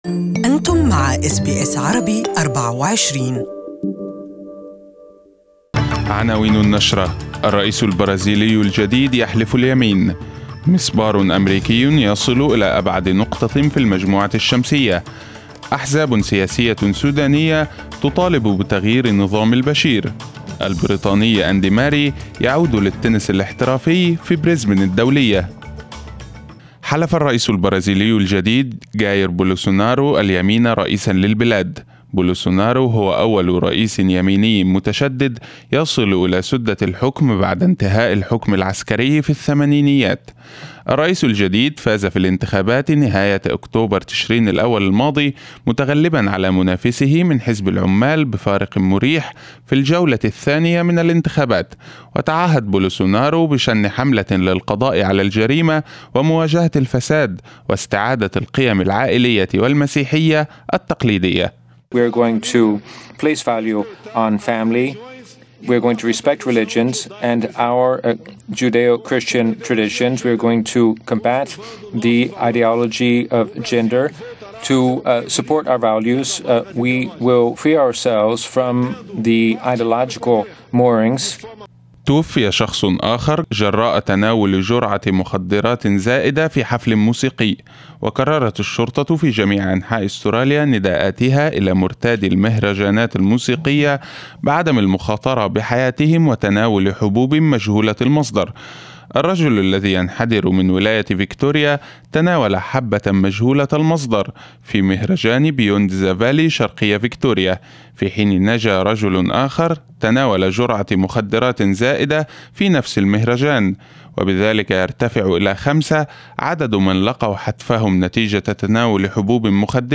First news bulletin of the day